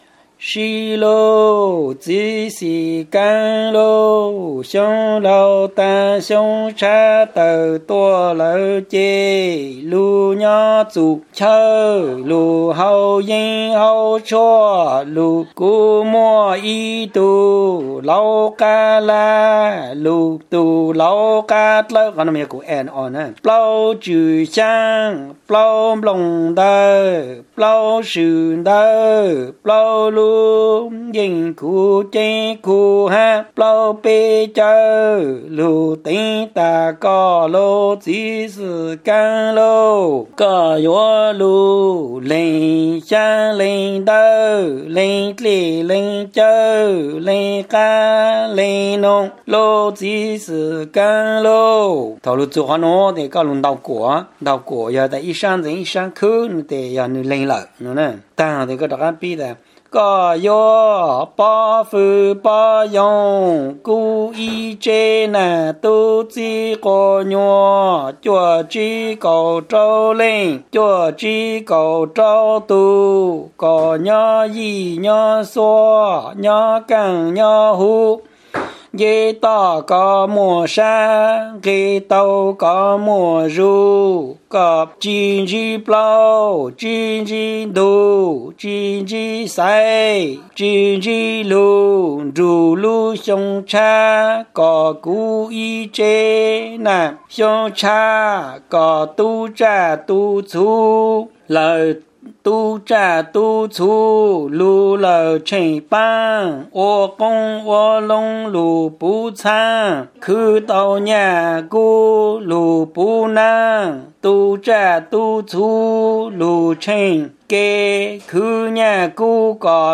During the day of the funeral, visitors will give money as donations to help the family. This is the hymn that is recited to the person making the donation.